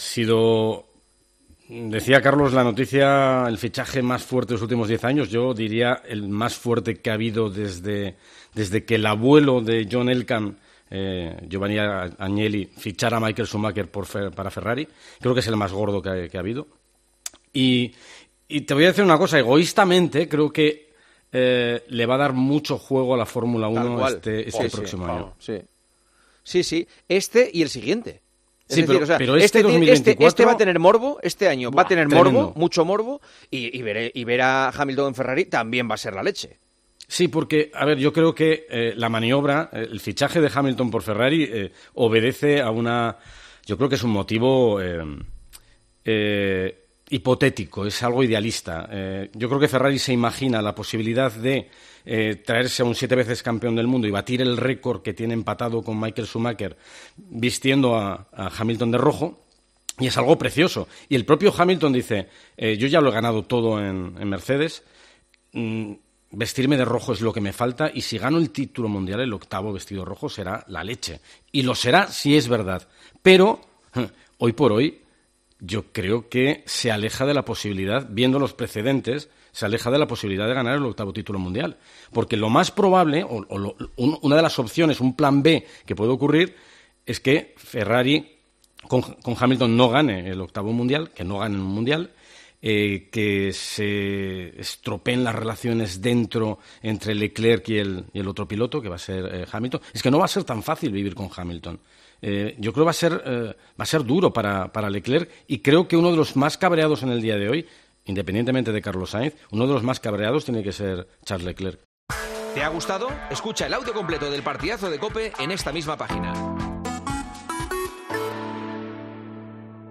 El narrador de la Fórmula 1 y colaborador de El Partidazo de COPE valoraron la llegada del piloto inglés a la escudería italiana a partir de 2025.